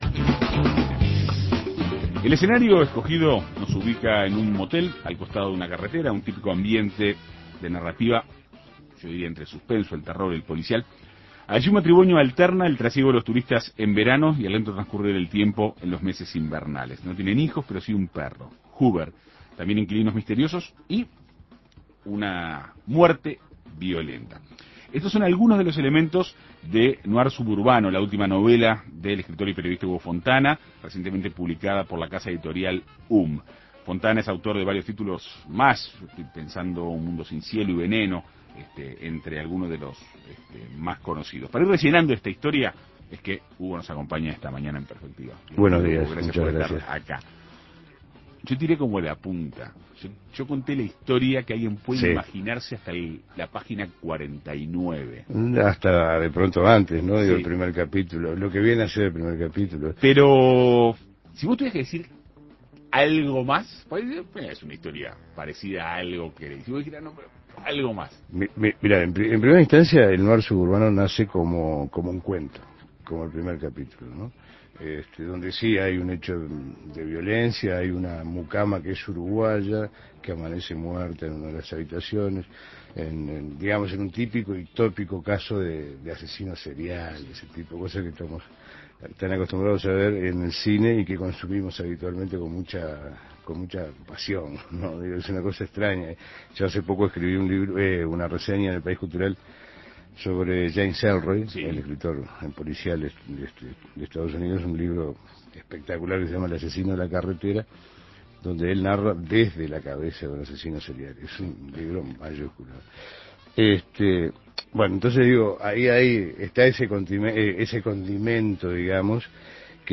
Para ir rellenando esta historia, En Perspectiva Segunda Mañana dialogó con el autor.